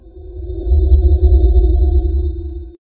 sy_move_high_s.ogg